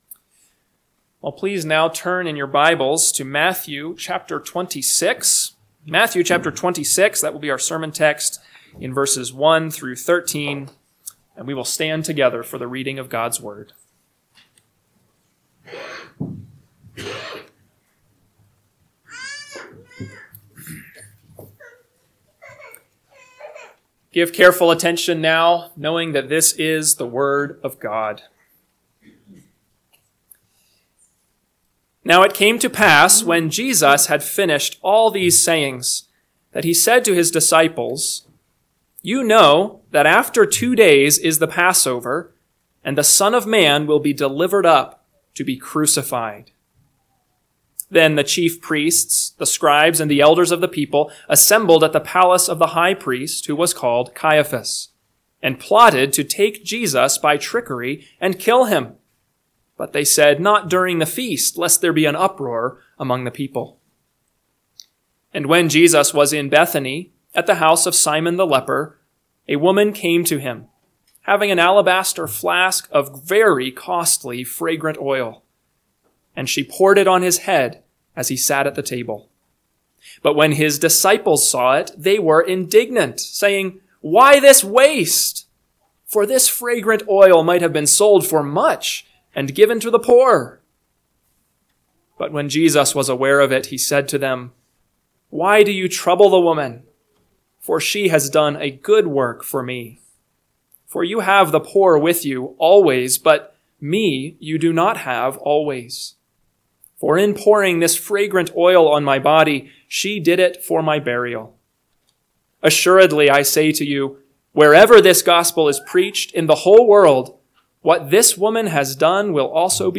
AM Sermon – 2/9/2025 – Matthew 26:1-13 – Northwoods Sermons